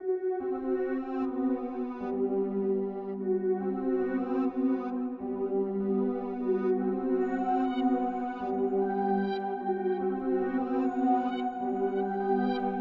Mural_Piano.wav